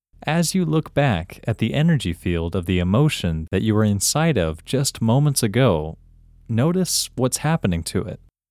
OUT – English Male 13